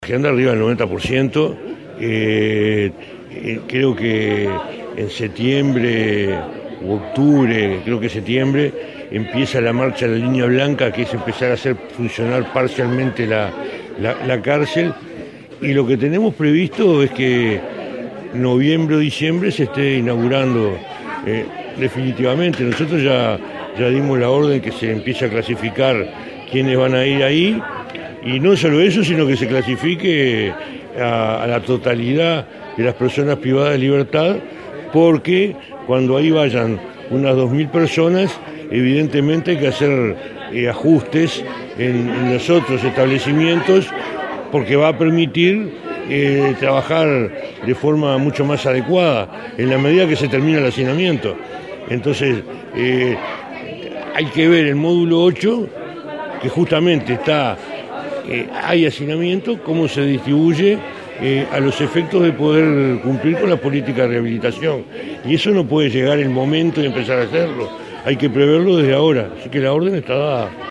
En setiembre comenzará a funcionar parcialmente el centro de reclusión de Punta de Rieles y se prevé que en diciembre se inaugure totalmente, confirmó el ministro Bonomi a la prensa. Agregó que dio la orden de que se clasifique a las personas privadas de libertad que serán trasladadas al centro, que tendrá una capacidad de 2.000 personas.